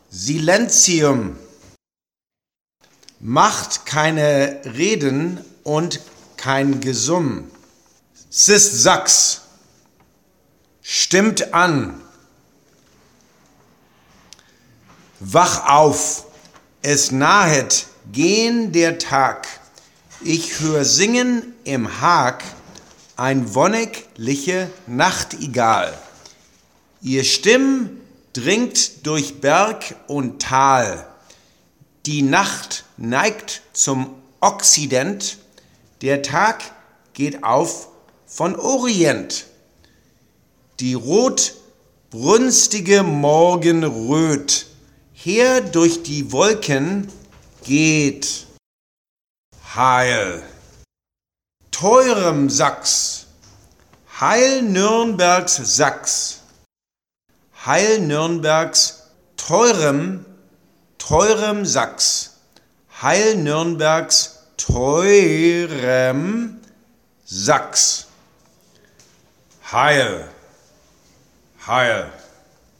3:33 Psalm 98 - Sing Unto the Lord - SOPRANO 3:33 Psalm 98 - Sing Unto the Lord - ALTO 3:33 Psalm 98 - Sing Unto the Lord - TENOR 3:33 Psalm 98 - Sing Unto the Lord - BASS 1:12 Wagner - Die Meistersinger Pronunciation - Alto Line
Wagner-Die+Meistersinger+Pronunciation-Alto+text.mp3